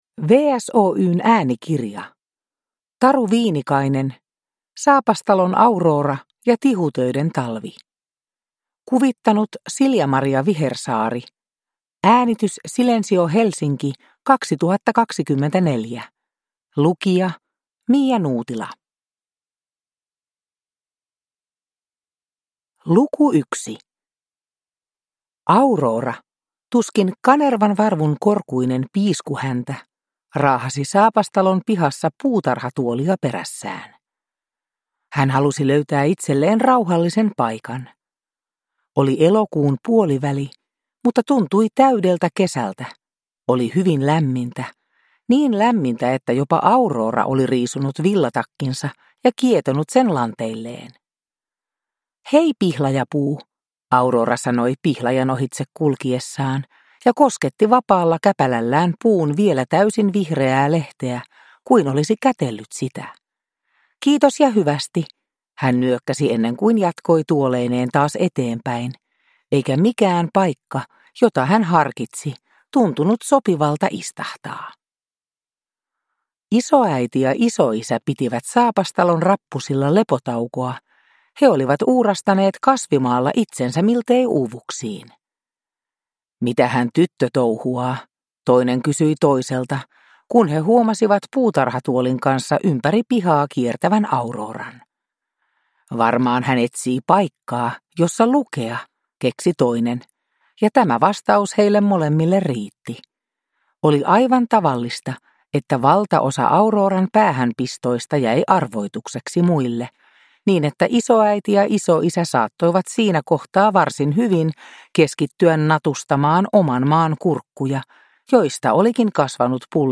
Saapastalon Aurora ja tihutöiden talvi (ljudbok) av Taru Viinikainen